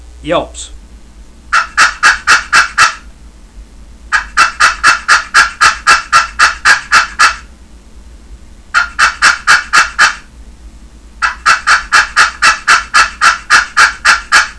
wwpushpinyelps15.wav